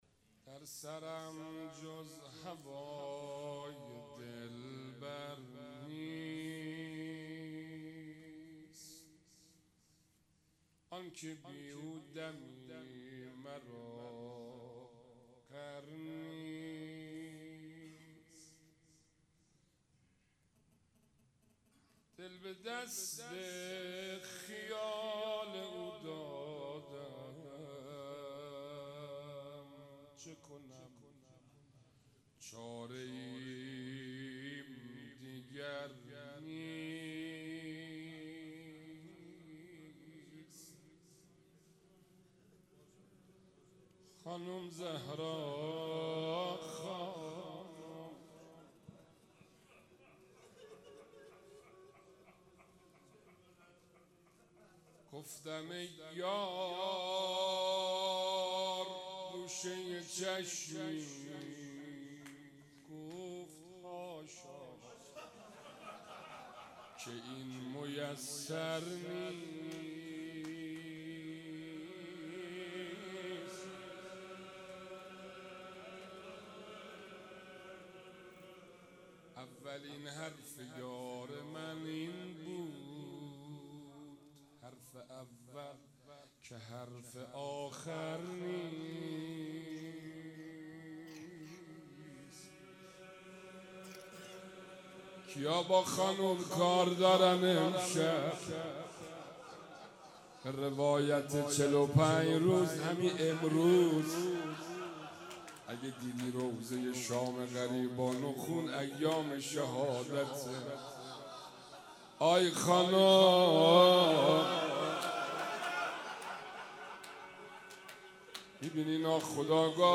هیئت انصار العباس(ع)/مراسم هفتگی
روضه حضرت زهرا(س)